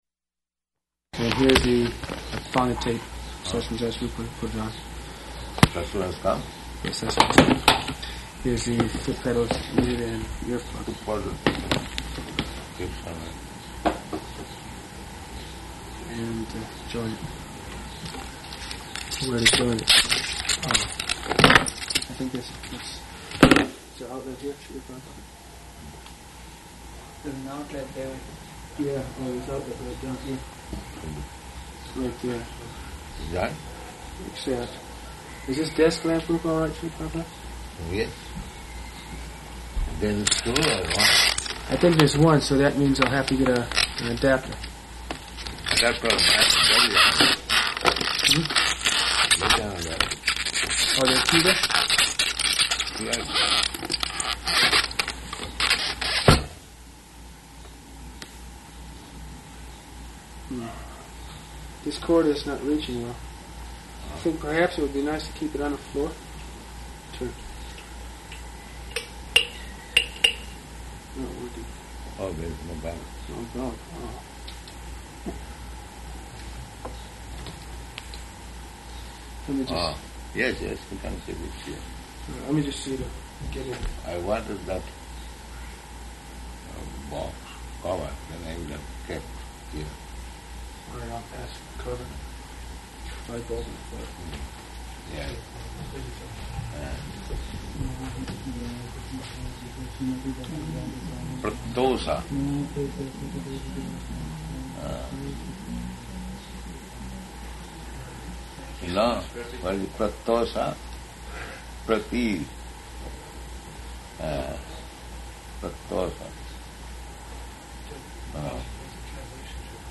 Room Conversation
Room Conversation --:-- --:-- Type: Conversation Dated: July 20th 1971 Location: New York Audio file: 710720R1-NEW_YORK.mp3 Devotee (1): And here is the...